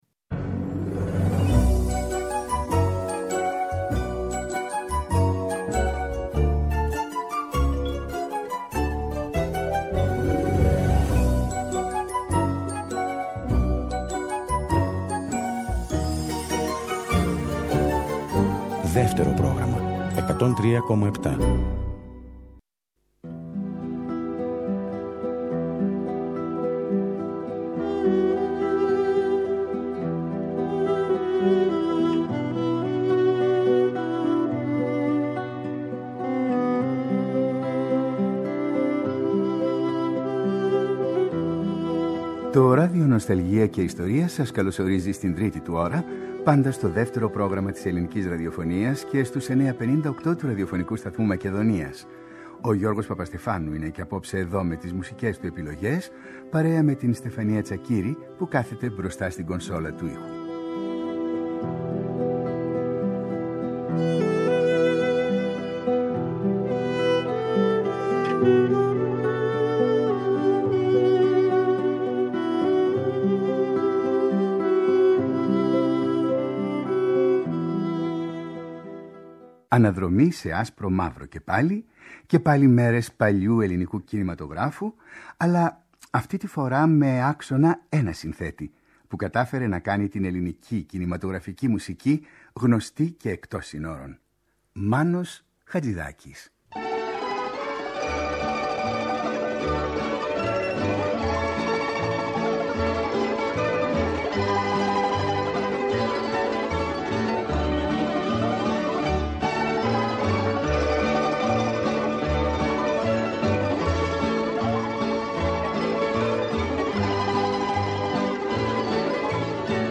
Από το Αρχείο της Ελληνικής Ραδιοφωνίας και τη σειρά εκπομπών Ράδιο Νοσταλγία, ακούμε την εκπομπή του Γιώργου Παπαστεφάνου που είναι αφιερωμένη στον κινηματογραφικό Μάνο Χατζιδάκι, παραγωγής 2004.